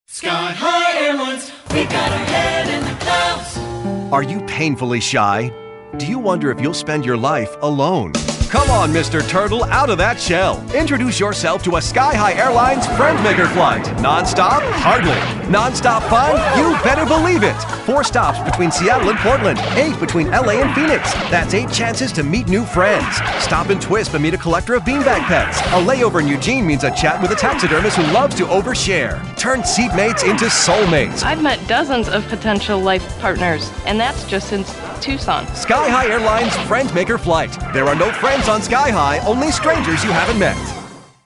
Radio Spots